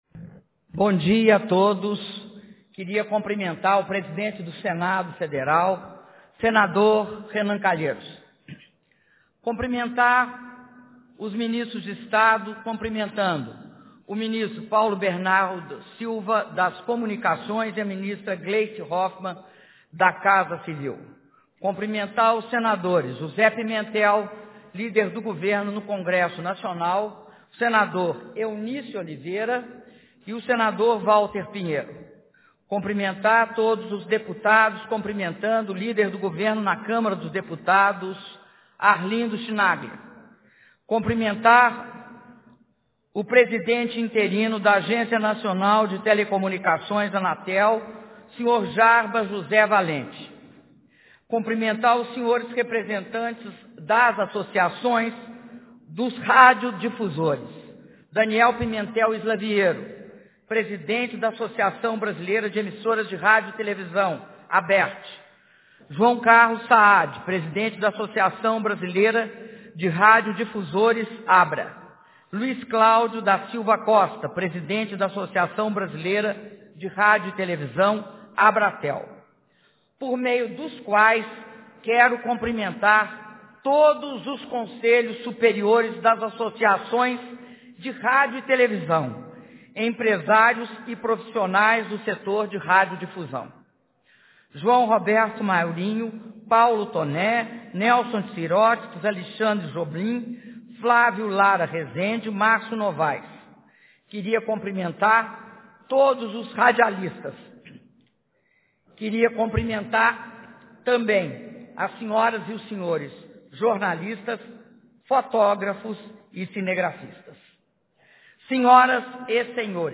Áudio do discurso da Presidenta da República, Dilma Rousseff, durante cerimônia de assinatura de decreto de adaptação das rádios AM para FM - Brasília/DF